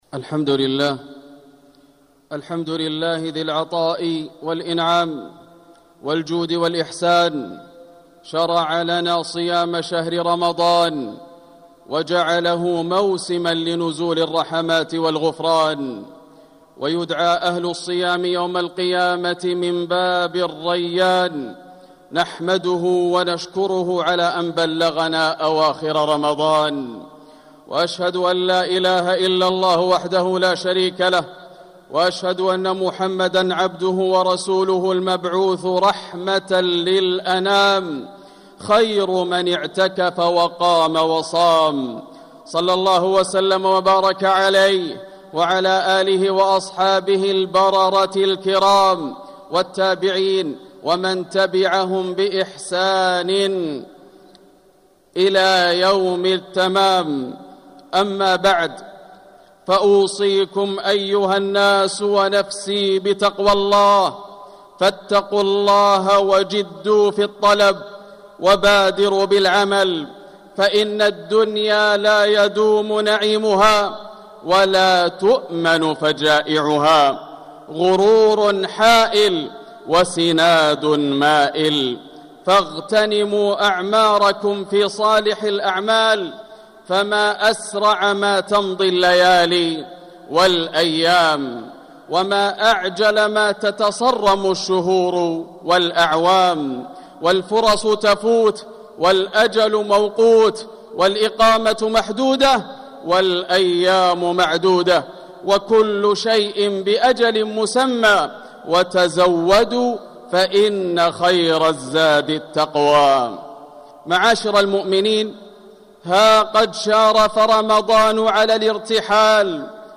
مكة: في توديع رمضان - ياسر بن راشد الدوسري (صوت - جودة عالية. التصنيف: خطب الجمعة